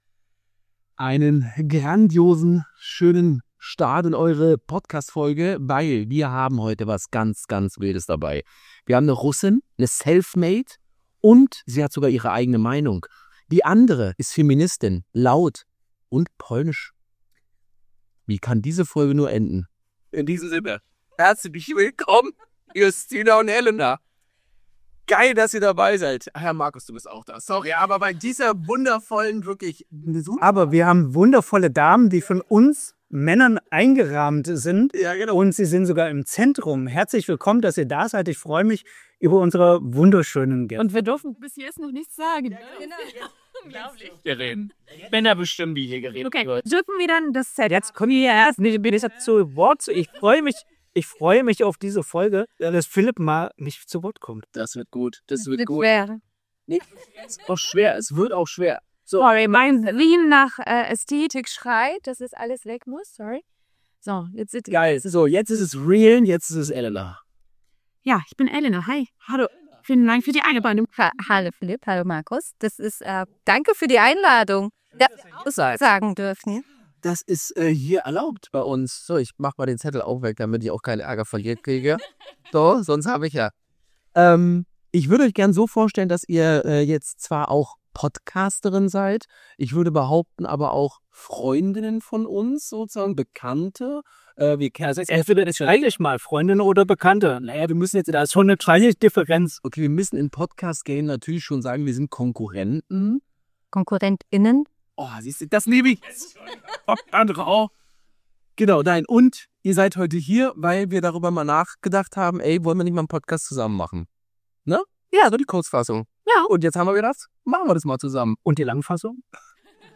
Ein Gespräch über Sichtbarkeit, Macht, Mut – und darüber, was passiert, wenn Rollenbilder endlich hinterfragt werden.